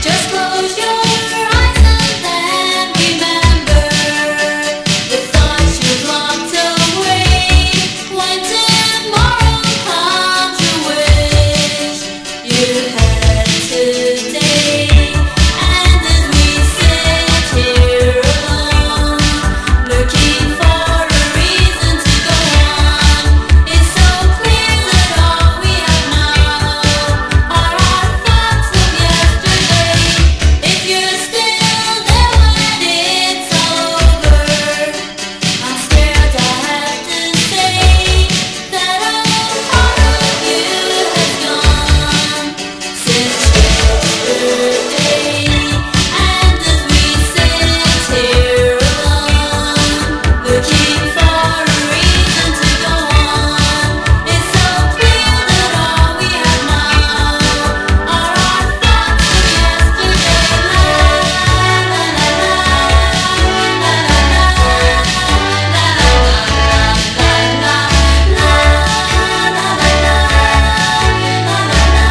WORLD / OTHER / NEW WAVE / AFRICA / BALEARIC